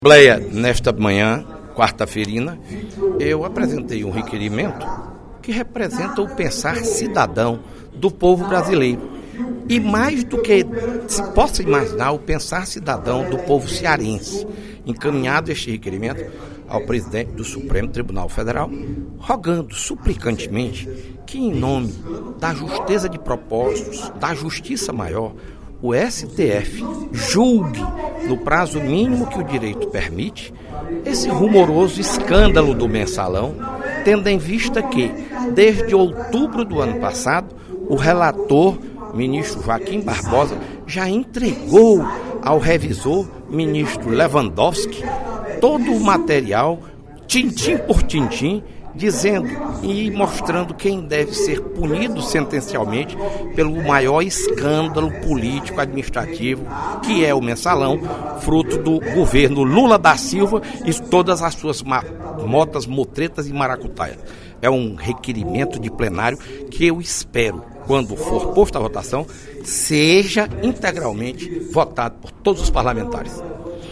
Na sessão plenária desta quarta-feira (04/04), o deputado Fernando Hugo (PSDB) anunciou que vai entregar ao presidente do Supremo Tribunal Federal (STF) ofício pedindo urgência no processo do mensalão, ocorrido durante o governo do ex-presidente Luiz Inácio Lula da Silva.